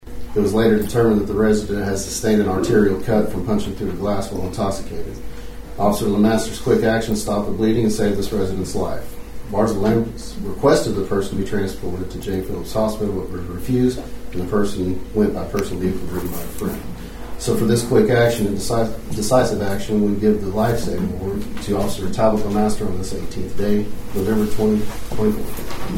At Monday's Dewey City Council meeting, the council approved the purchase of two used vehicles and upfitting for $44,000 for the Dewey Police department.